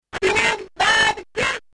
It sounds absolutely horrible...